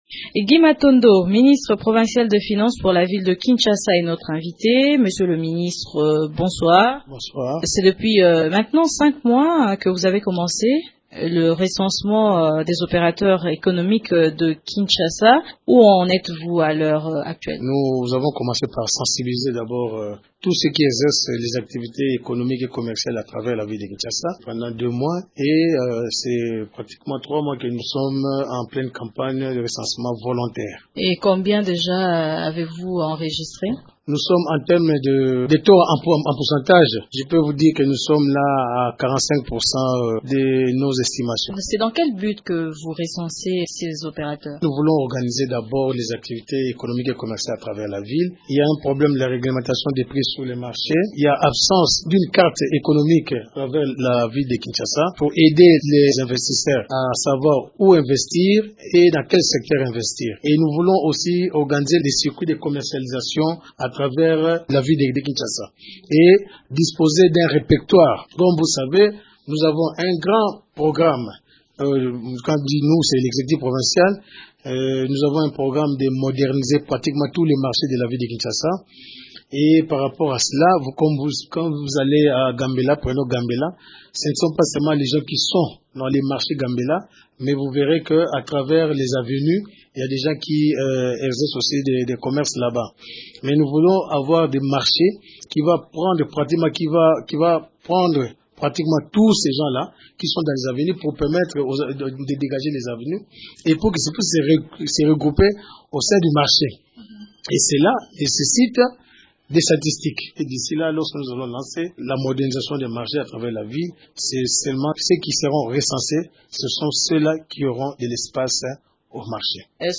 Ne devrait exercer très prochainement une activité commerciale dans la capitale congolaise que celui qui est reconnu par l’autorité provincial, a-t-il martelé, ce vendredi 18 mars sur Radio Okapi.
Entretien-Recensement-Guy-Matando-3-Min-30-Sec.mp3